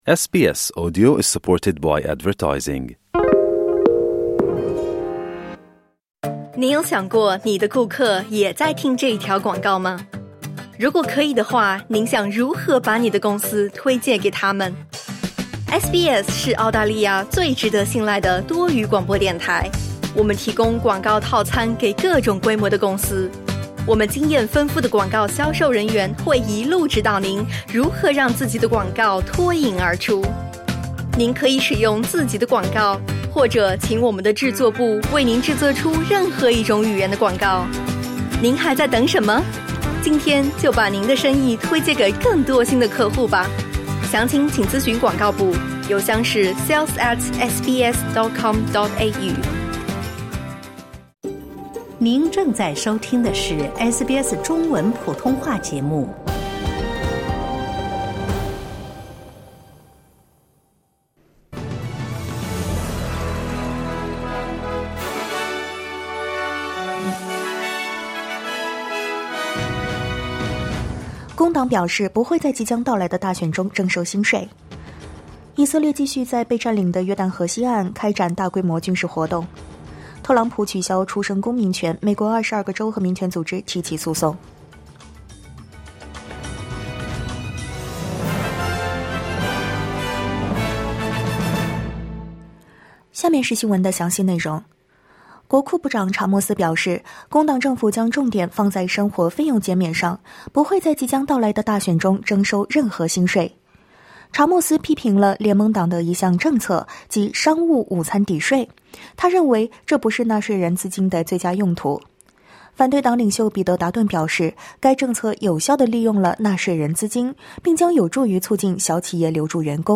SBS早新闻（2025年1月23日）